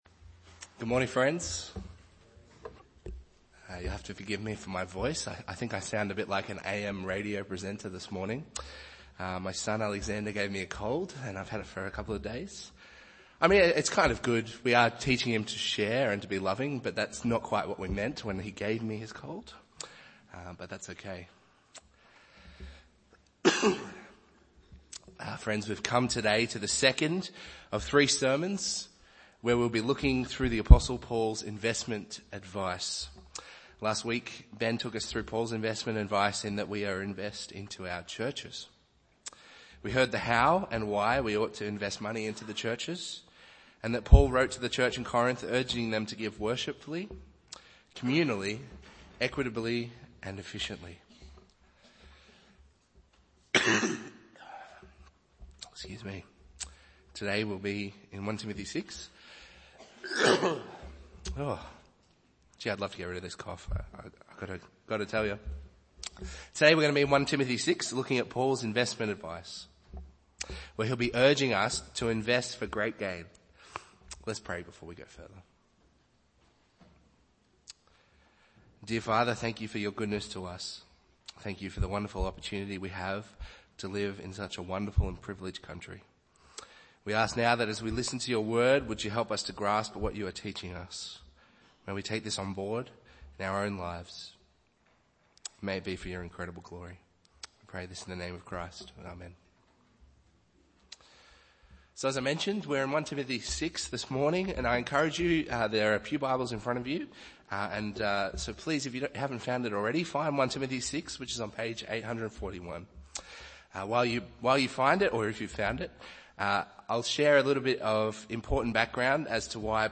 Bible Text: 1 Timothy 6:3-10 | Preacher